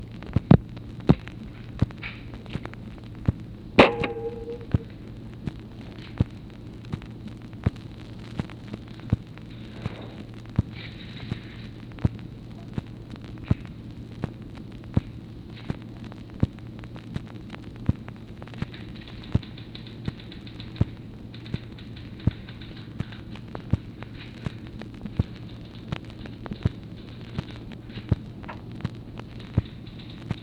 OFFICE NOISE, April 21, 1964
Secret White House Tapes | Lyndon B. Johnson Presidency